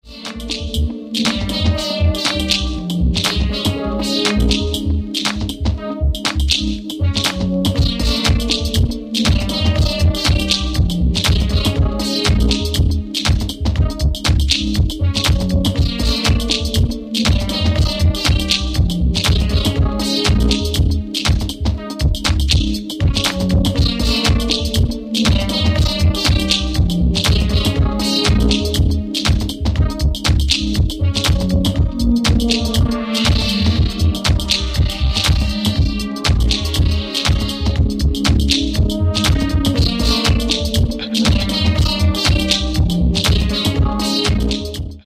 Groovy STyLe